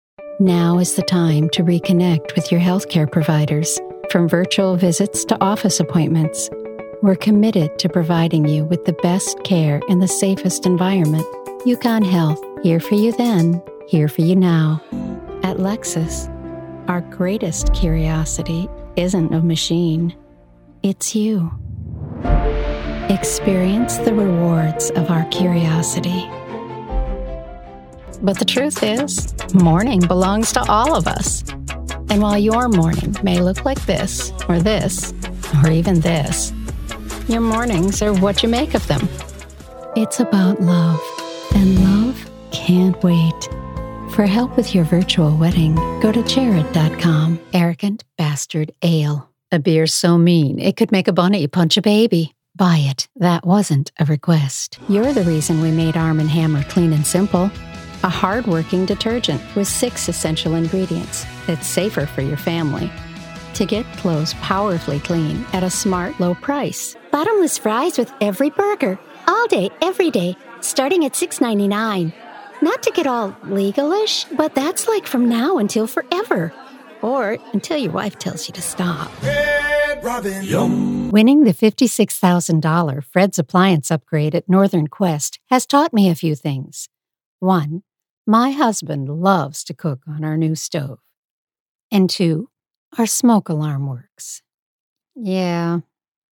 Commercial Demo
American Midwest, American-Southern (genl), Irish-American
Middle Aged